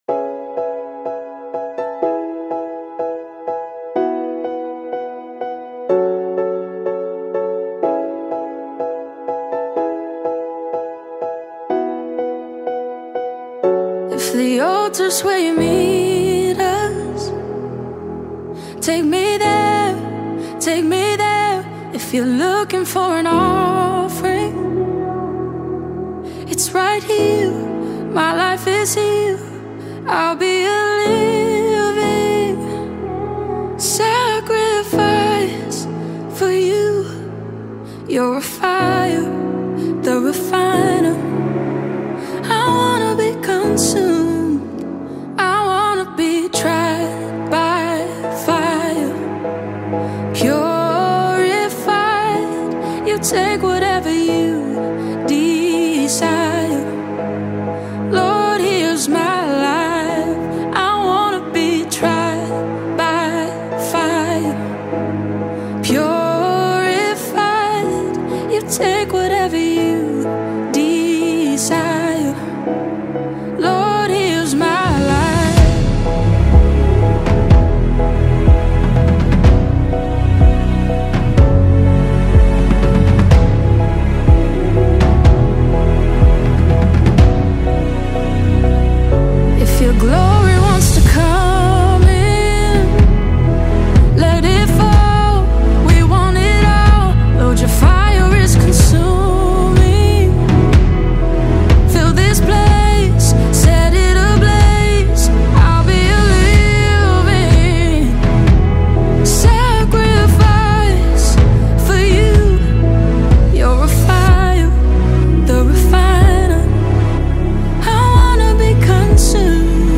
152 просмотра 105 прослушиваний 11 скачиваний BPM: 124